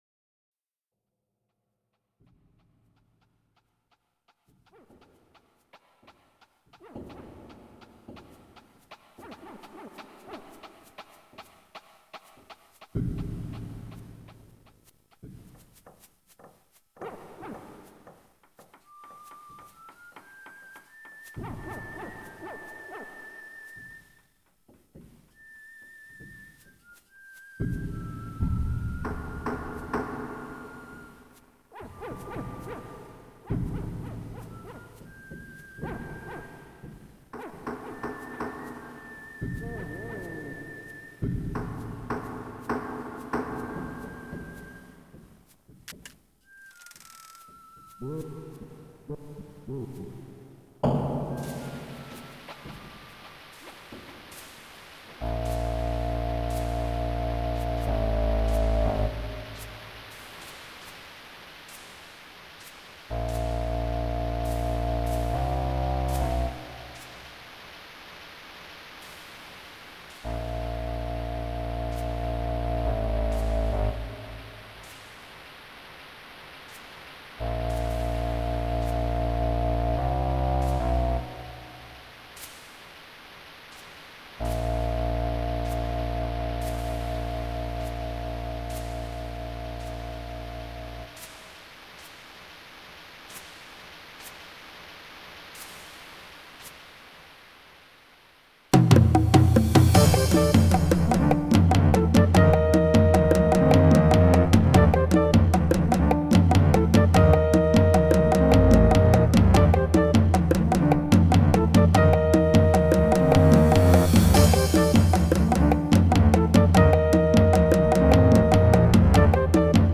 as recorded from the original Roland MT-32 score!